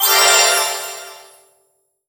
magic_general_item_collect_02.wav